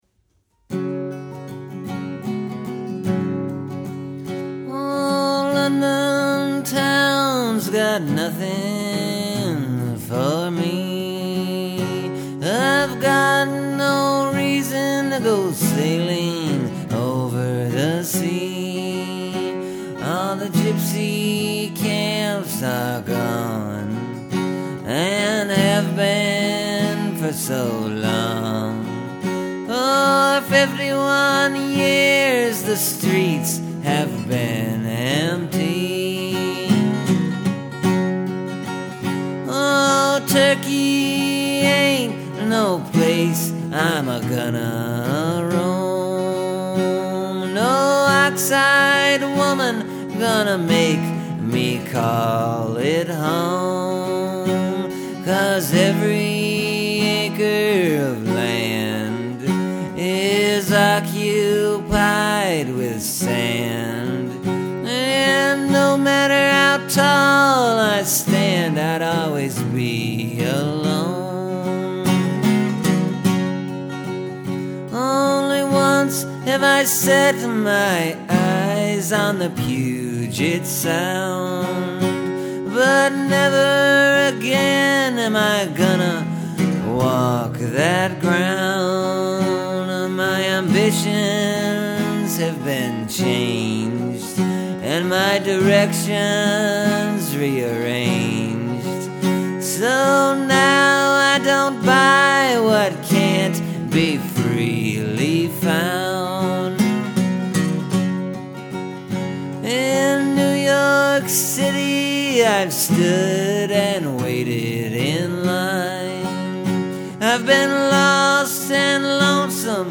Here’s something a little different than the other songs I’ve written lately. Not so much in the singing or playing, but the way I wrote the song and what goes into it and comes out of it.